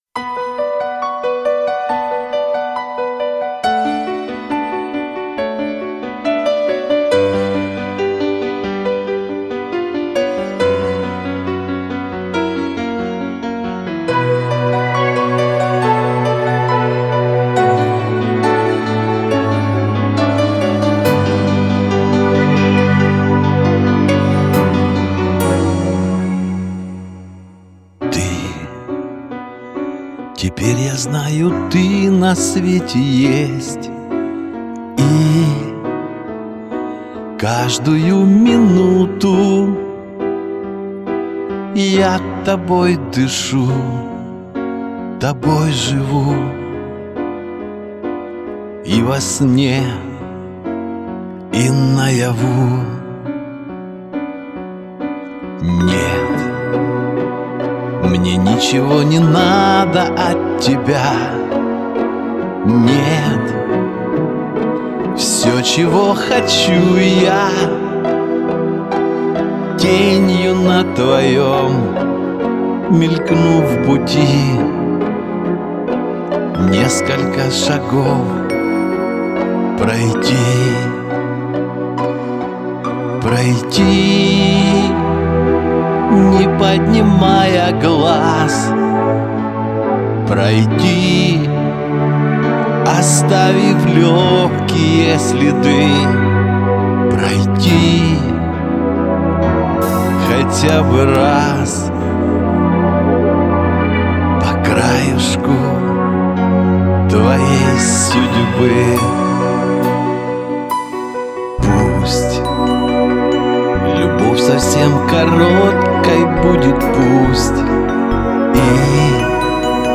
спокойно и проникновенно.